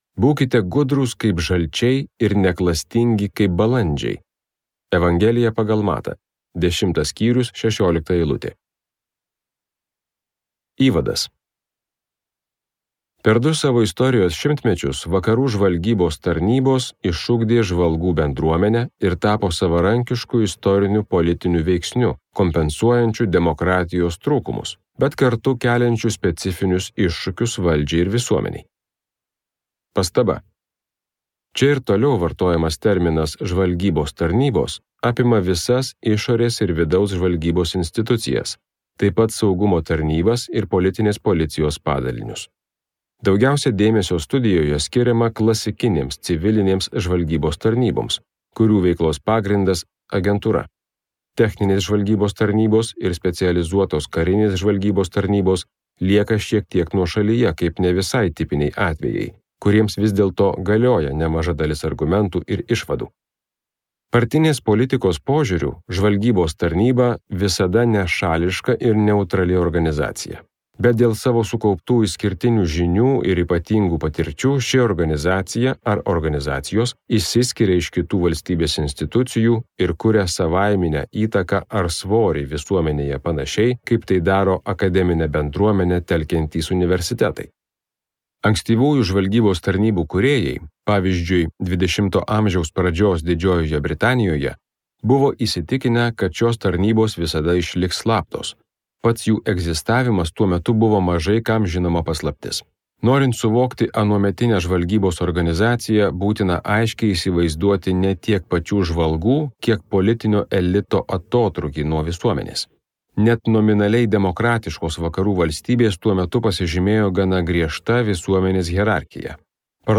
Skaityti ištrauką play 00:00 Share on Facebook Share on Twitter Share on Pinterest Audio Žvalgyba Vakaruose.